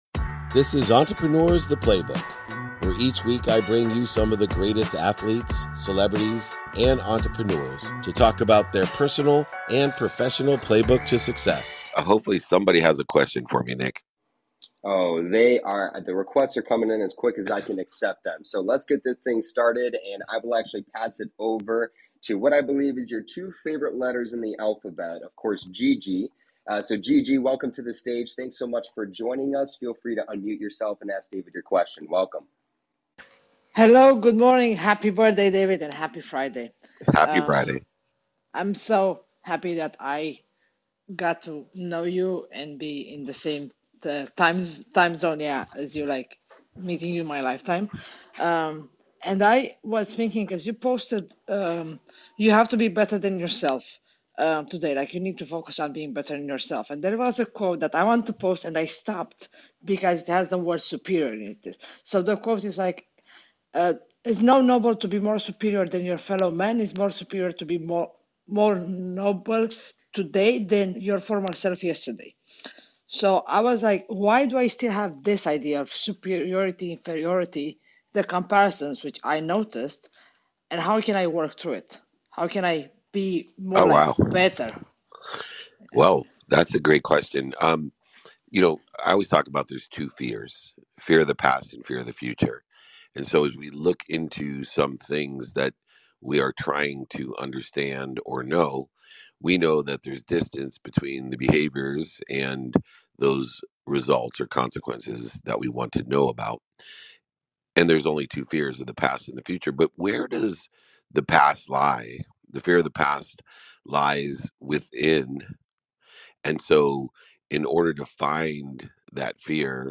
In today's episode, I take questions from around the room, diving into topics like overcoming personal fears, understanding how we think and behave, and keys to business success. We talk about moving past old fears to better our future, how feelings of less or more than others can steal our happiness, and the give-and-take in business. This discussion also covers ways to lessen fear's hold, change negative thoughts to positive ones, and the power of talking daily with family to strengthen bonds.